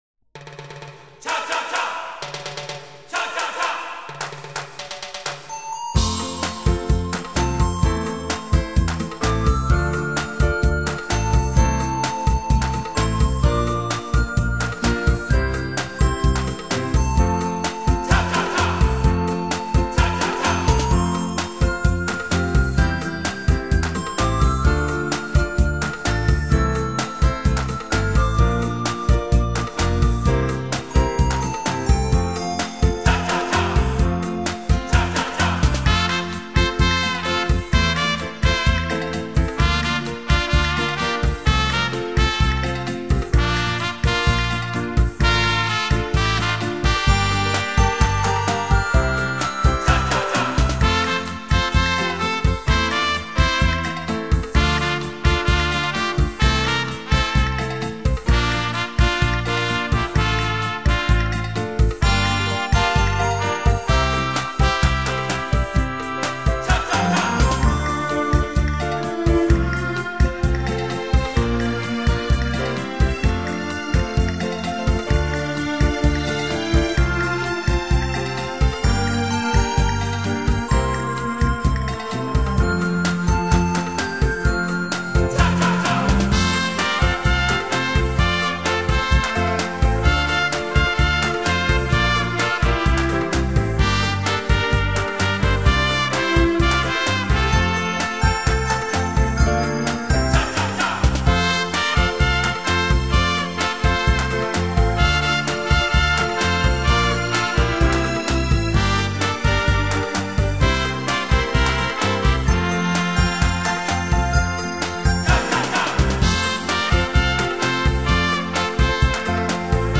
音乐类型：舞曲节奏